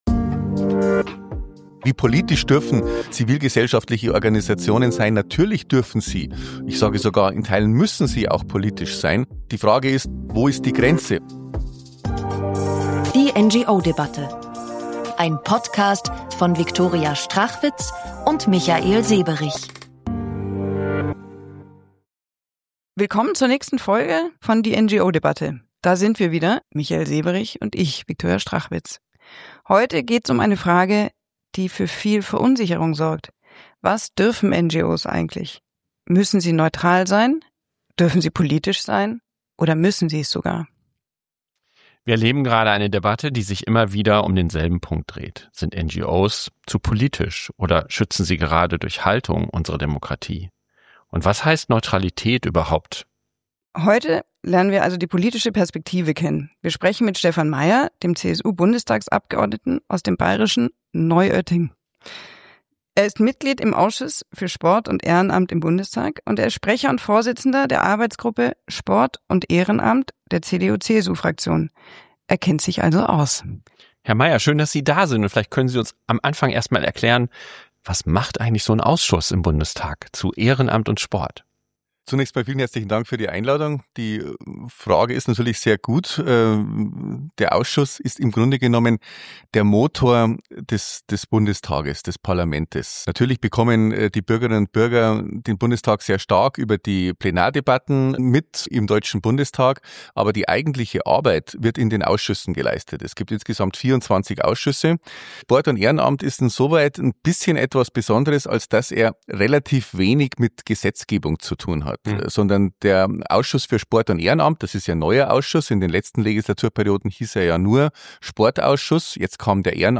Gemeinsam mit unserem Gast, dem Bundestagsabgeordneten Stephan Mayer (CSU), sprechen wir über das Ziel der Kleinen Anfrage der CDU/CSU-Fraktion vor knapp einem Jahr und darüber, wo die CSU die Grenze zwischen politischem Engagement und parteipolitischer Neutralität zieht.